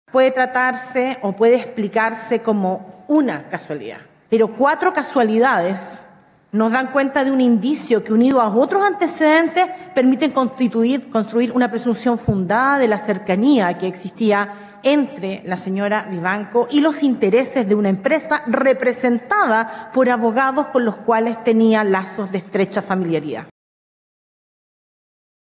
Ya en la sala, el protagonismo fue exclusivo de los querellantes, y el Consejo de Defensa del Estado centró su alegato en la existencia de indicios múltiples y concordantes que, analizados en conjunto, permitirían configurar una presunción fundada de actuaciones impropias.